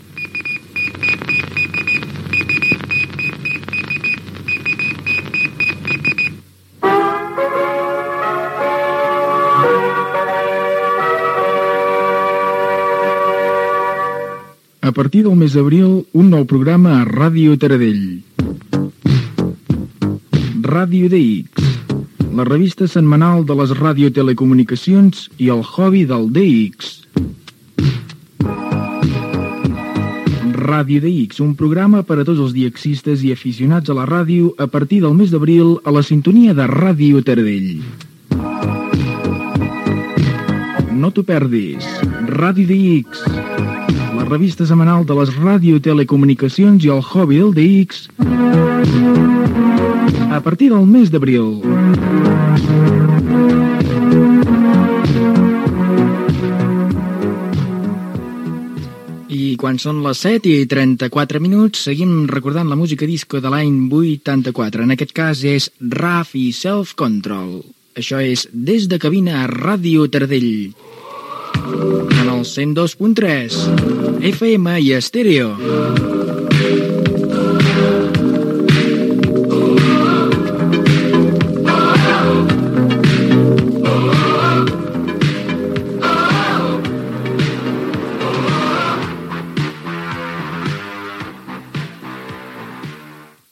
Promoció de l'inici d'emissió del programa "Ràdio DX", hora, identificació del programa i de l'emissora i tema musical.
Musical